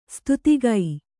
♪ stutigai